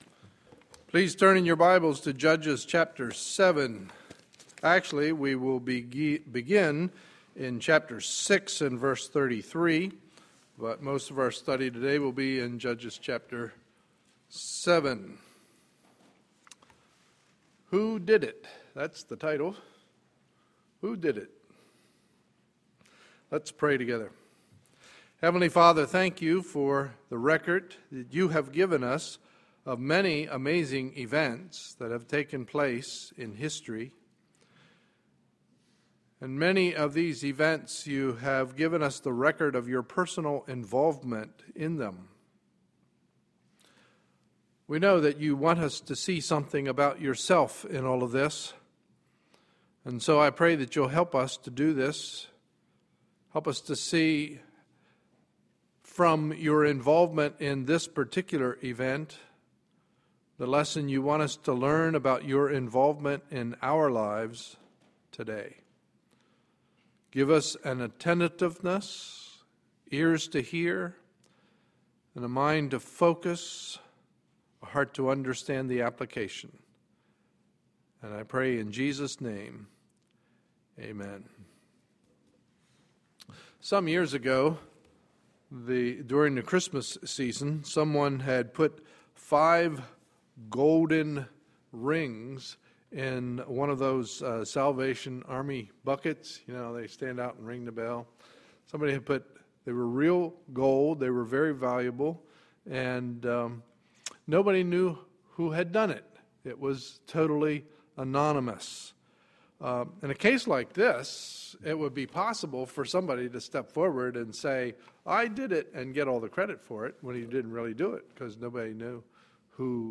Sunday, July 14, 2013 – Morning Service